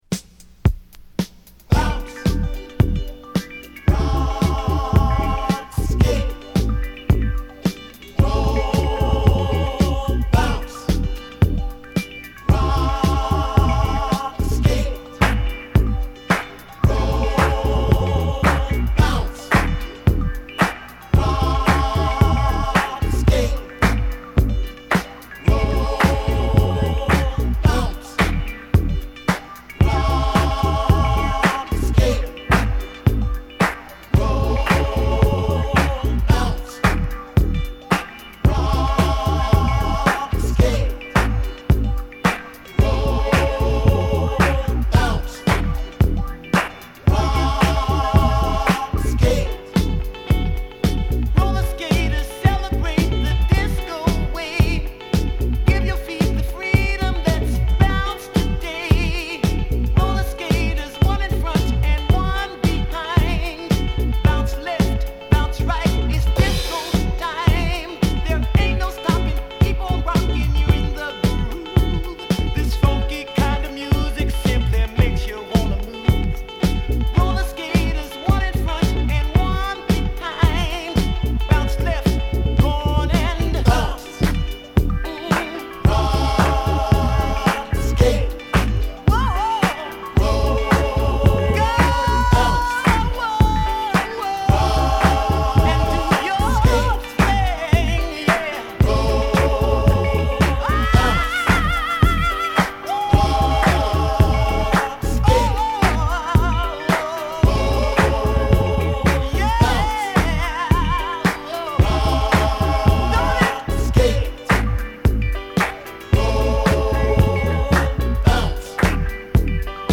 タイトなドラムに華を添えるクラップ&パーカッション、図太いMoogベースがカッコ良い彼らの代表曲！